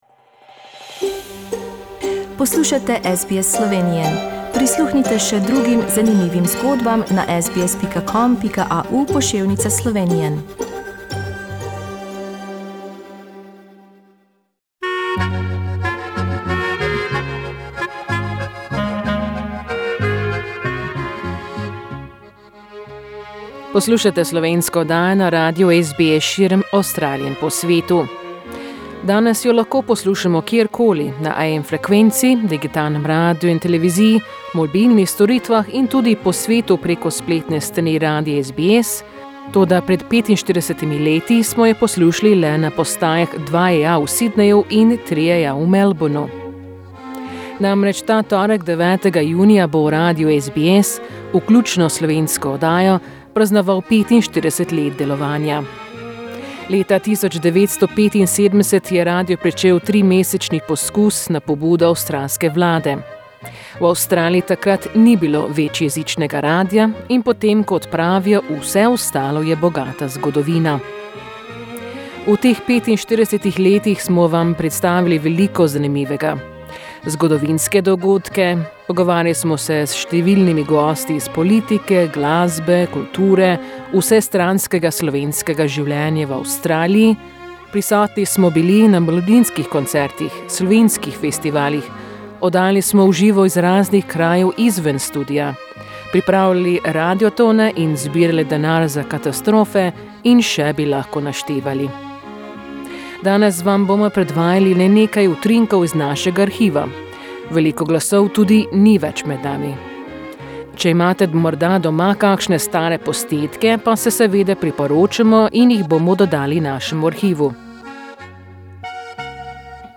Junija 1975 smo prvič slišali slovensko besedo na Radiu 2EA v Sydneyu in 3EA v Melbournu. Te dni praznujemo 45. obletnico in za to praznovanje smo pripravili prispevek iz našega arhiva skozi leta.